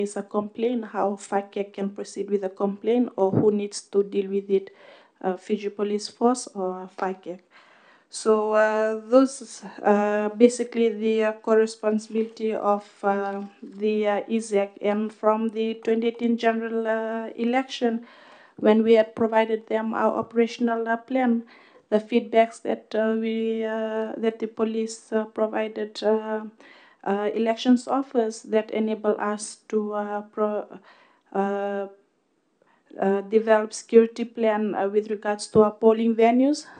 This has been highlighted by Acting Supervisor of Elections Ana Mataiciwa while making a submission to the Standing Committee on the Electoral Commission’s 2020–2021 annual report.
Acting Supervisor of Elections Ana Mataiciwa.